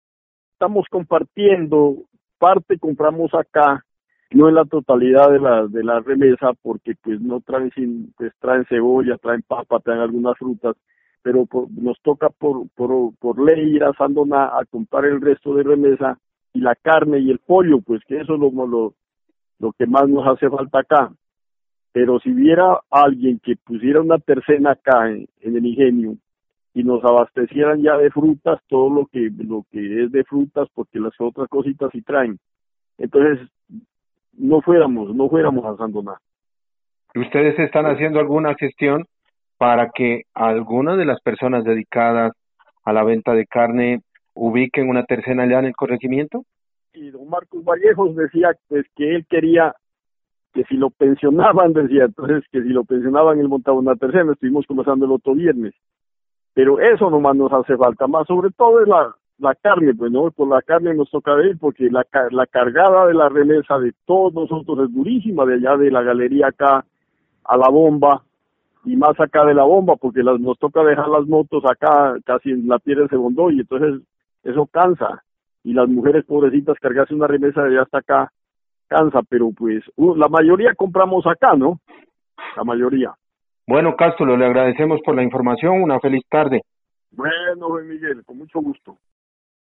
A los líderes comunitarios les hicimos la misma pregunta: ¿En la actualidad en donde se abastecen de alimentos las familias de su corregimiento?